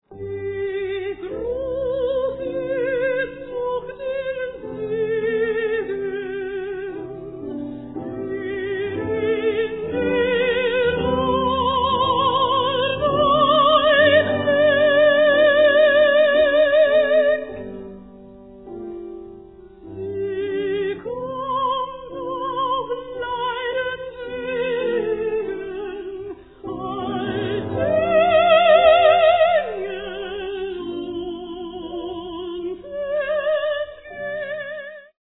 mezzo soprano
piano